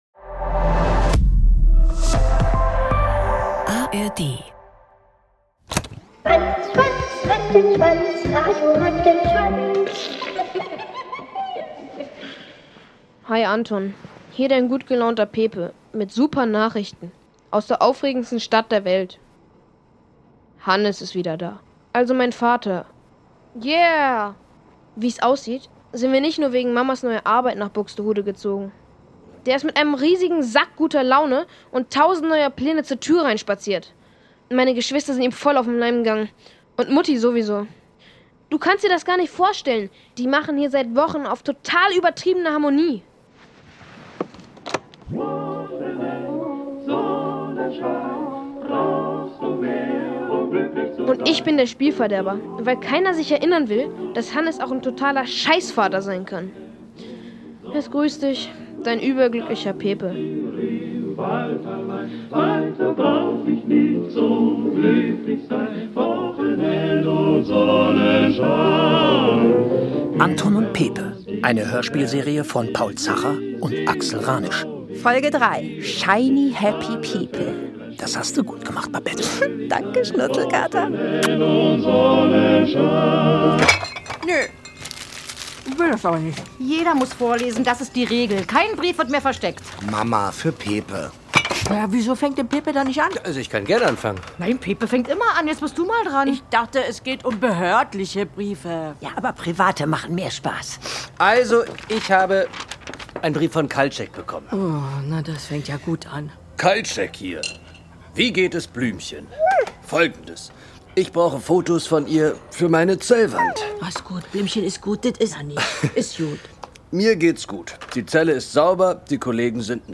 NDR Hörspiel Box